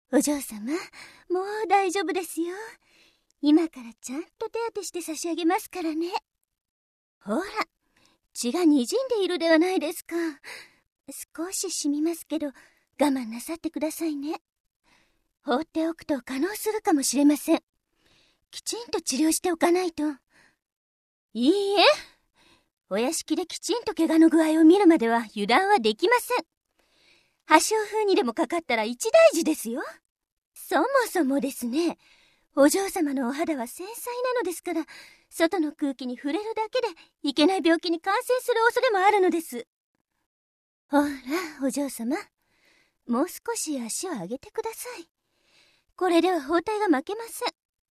就是ミ-ウ那个环节里面女仆装的那个 声音很御姐呢 有爱